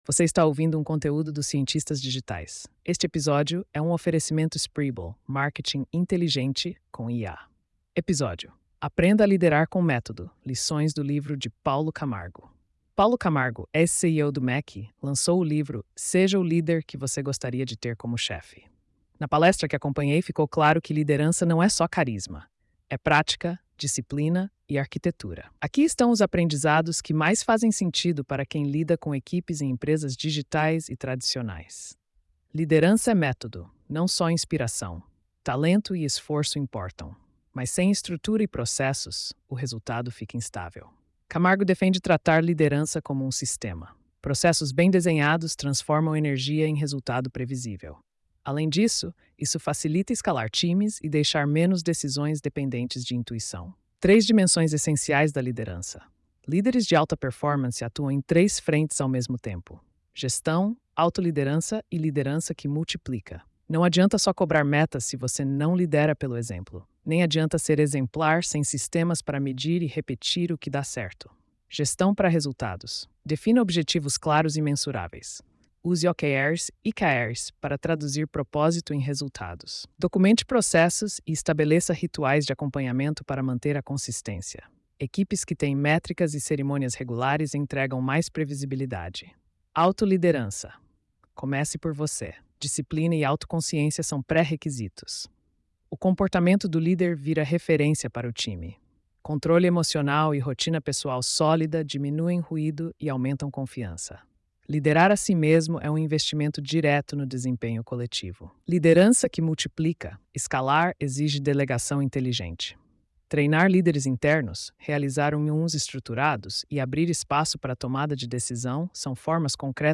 post-4403-tts.mp3